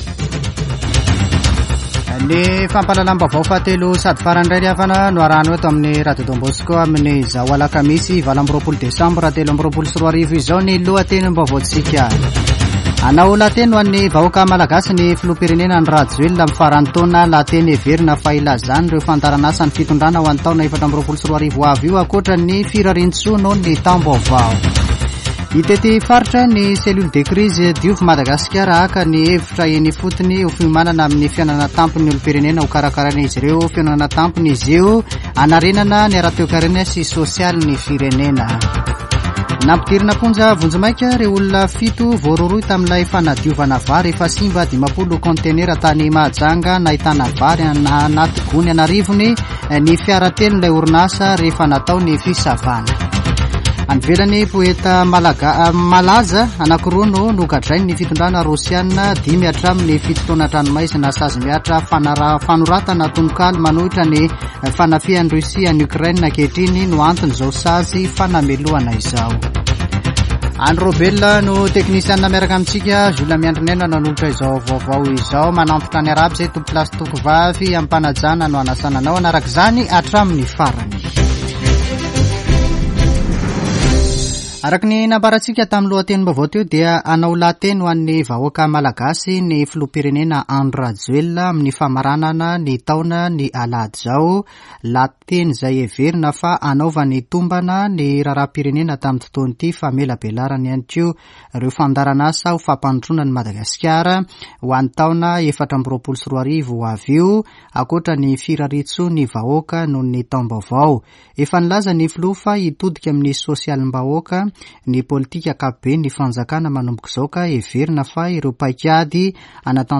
[Vaovao hariva] Alakamisy 28 desambra 2023